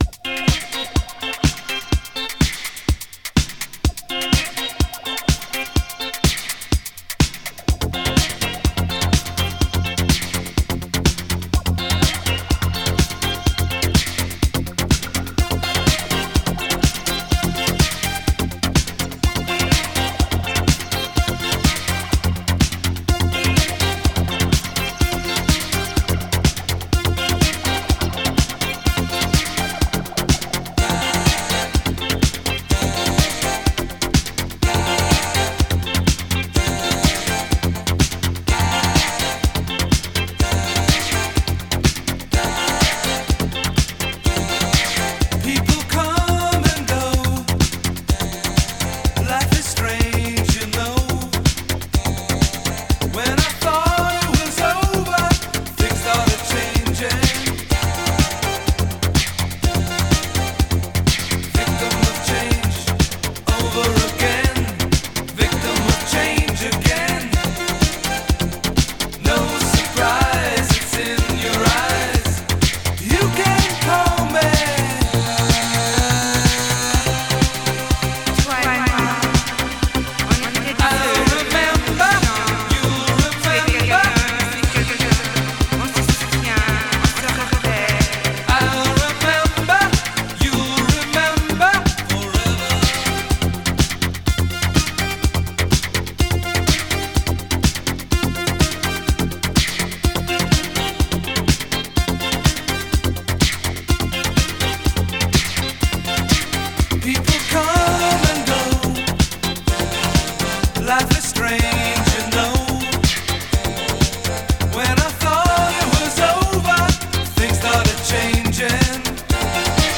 So banging!
Filed under bangers, disco